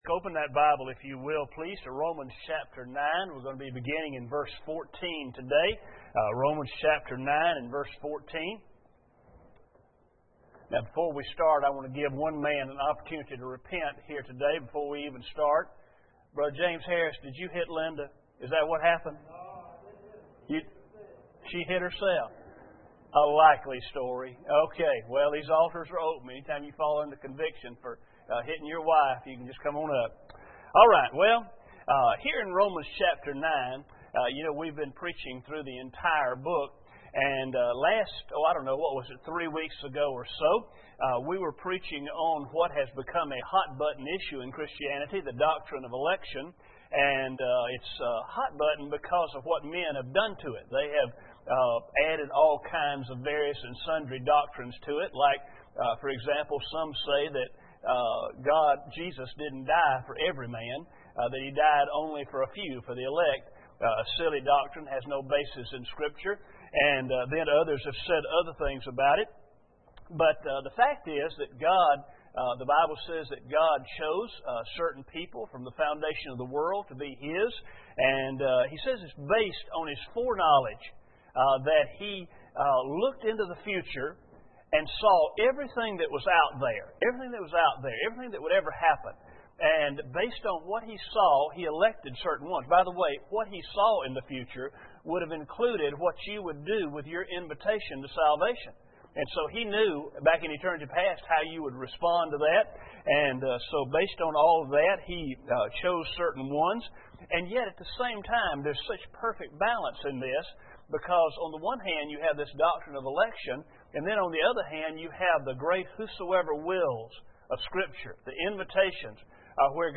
Romans 9:14-24 Service Type: Sunday Morning Bible Text